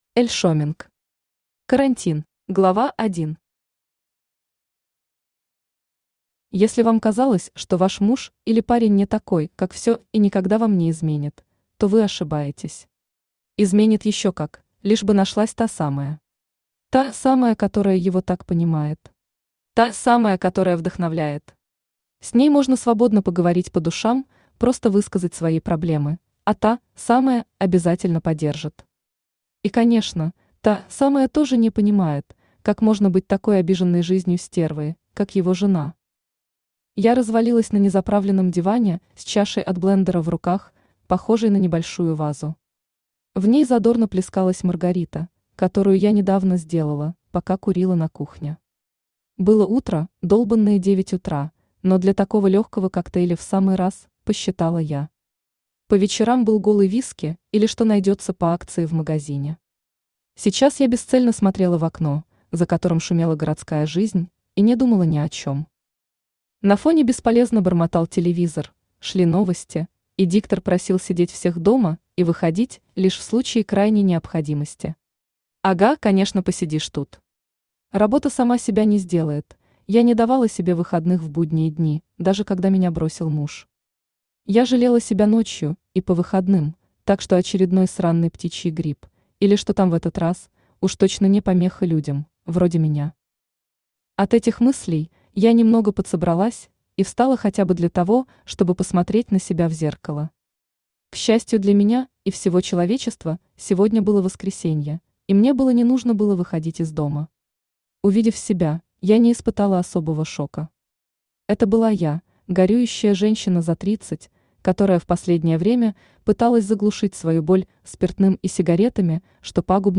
Aудиокнига Карантин Автор Эль Шеминг Читает аудиокнигу Авточтец ЛитРес.